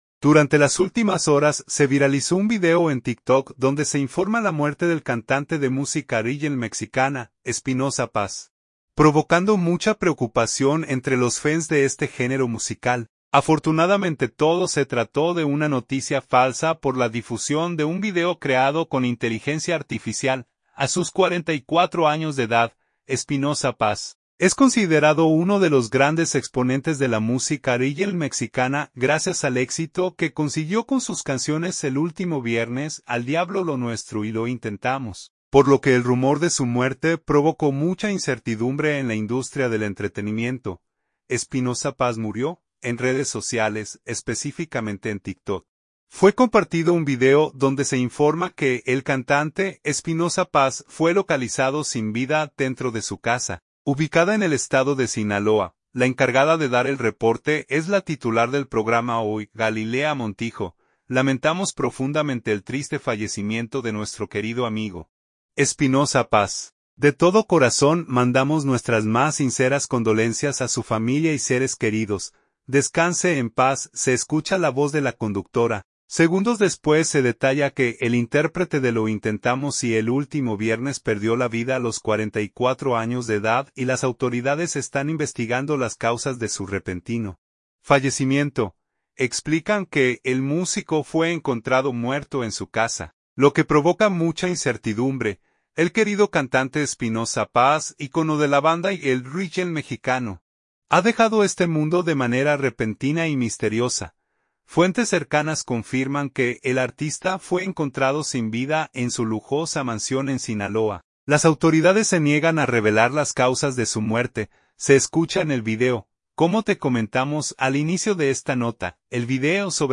Afortunadamente todo se trató de una noticia falsa por la difusión de un video creado con inteligencia artificial
Como te comentamos al inicio de esta nota, el video sobre la muerte del cantante, Espinoza Paz, fue creado con inteligencia artificial y se trata de una noticia falsa.